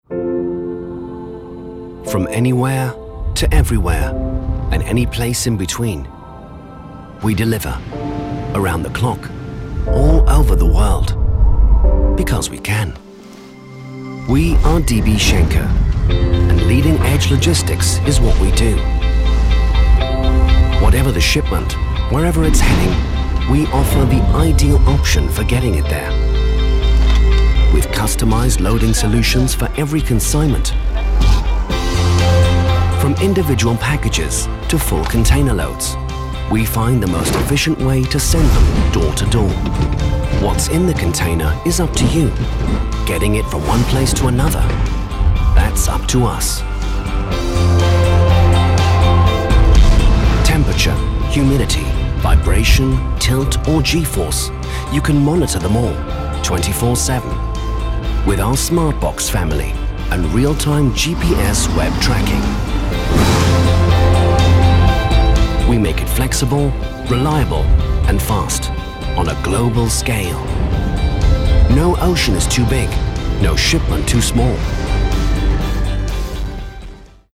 Corporate Logistics Film
English - British and European
Voice Age
Middle Aged
My voice is friendly, trustworthy, and naturally conversational, making it well suited to brands and organisations looking to communicate clearly and authentically.